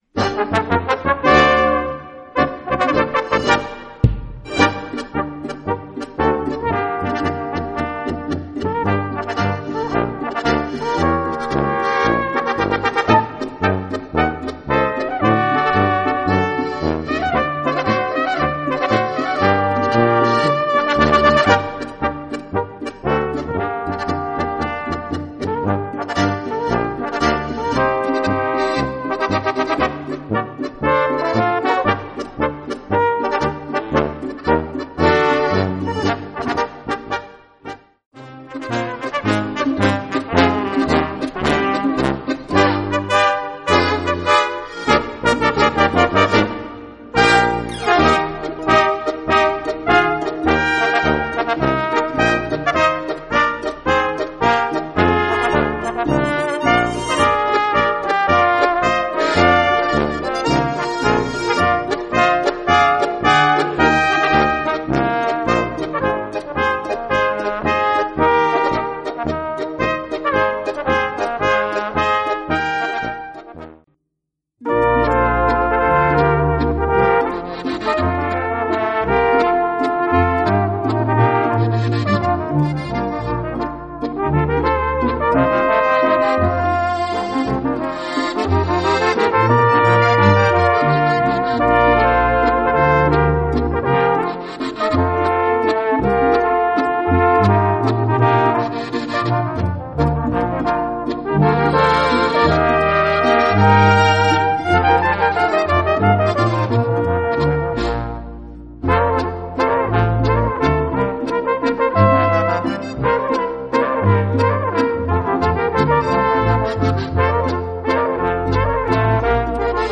Besetzung: Volksmusik Tanzlmusik